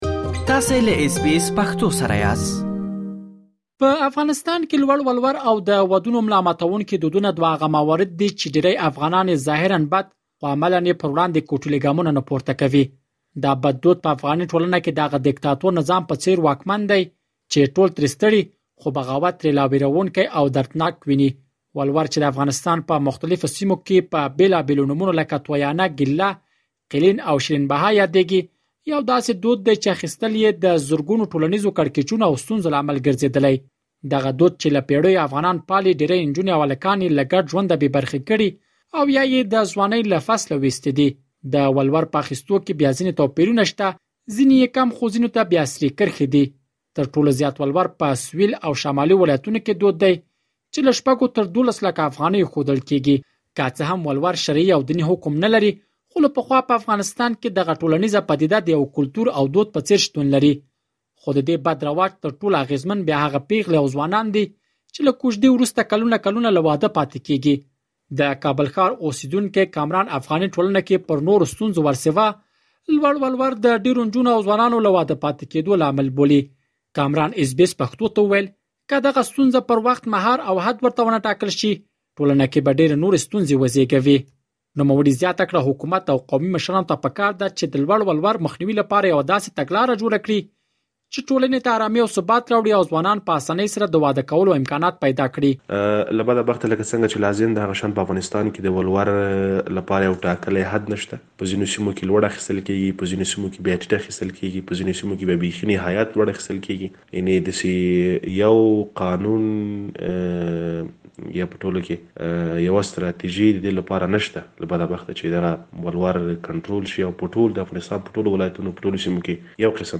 خو ددې بد رواج تر ټولو اغیزمن بیا هغه پیغلې او ځوانان دي چې له کوژدې وروسته کلونه کلونه له واده پاتې کیږي مهرباني وکړئ په دې اړه لا ډېر معلومات دلته په رپوټ کې واوروئ.